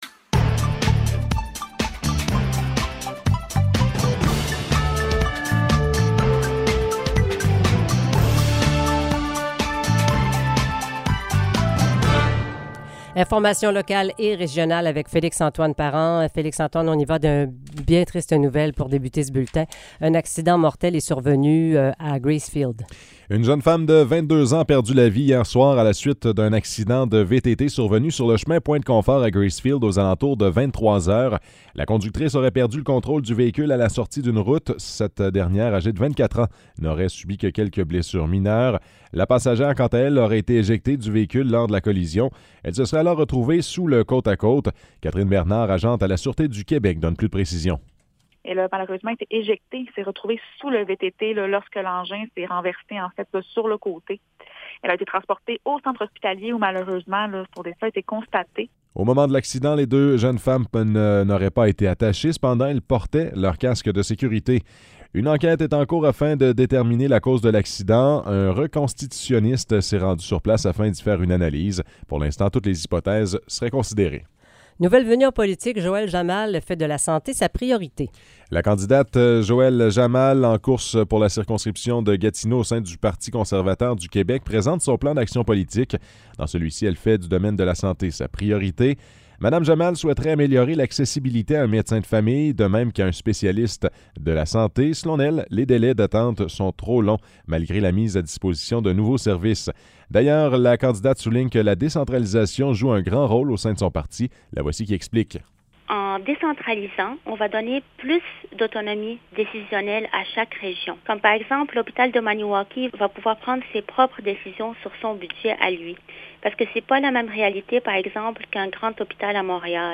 Nouvelles locales - 19 juillet 2022 - 9 h